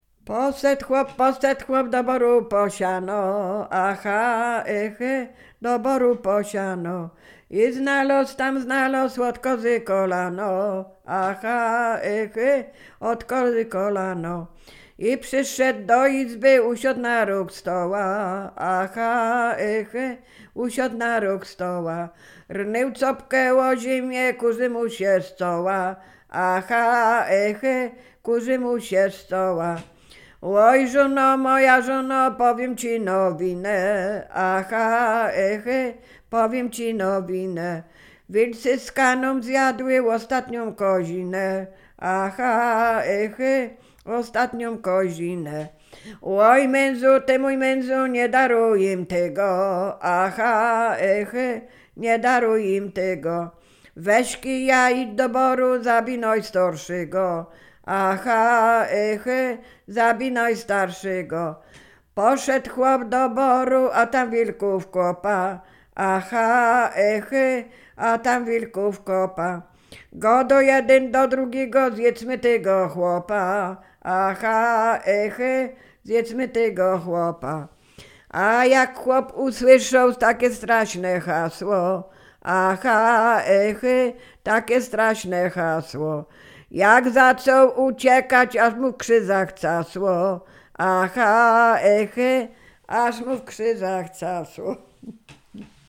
Sieradzkie
liryczne żartobliwe